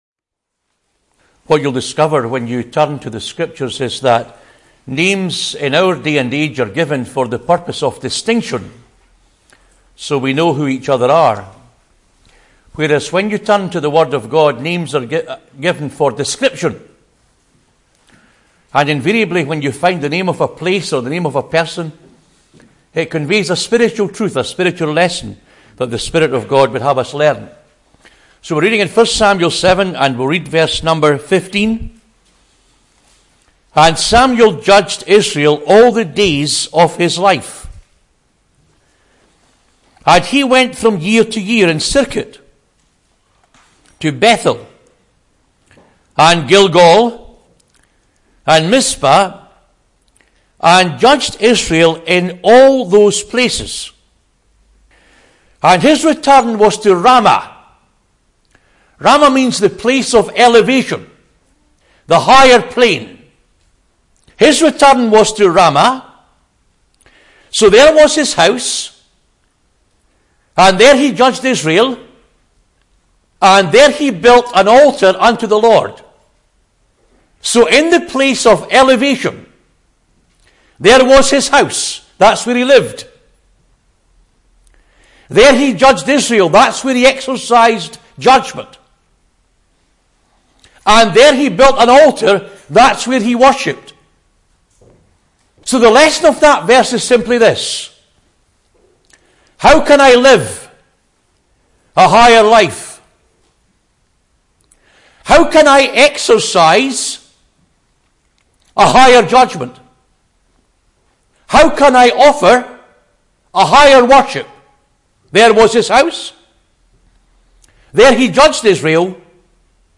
He finds 4 principles under each topic as he works through the chapter. A helpful, challenging message, especially in relation to communion with Christ (Message preached 27th Oct 2017)